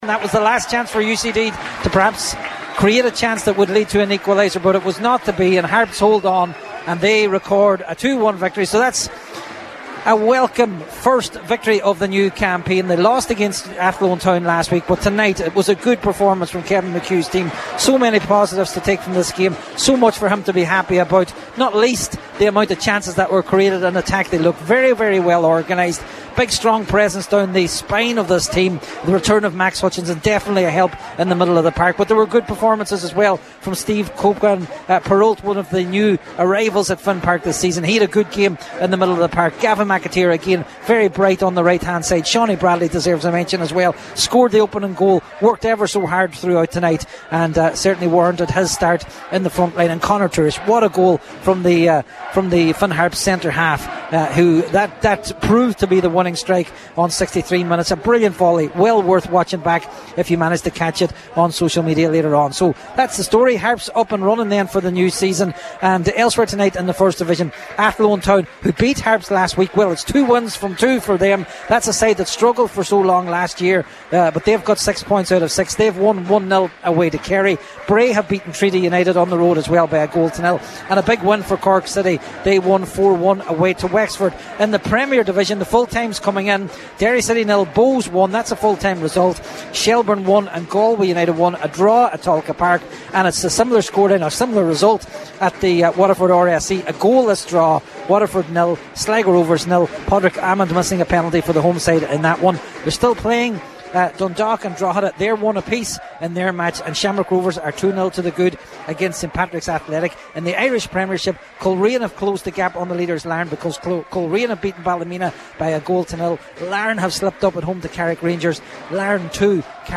was live at full time at Finn Park…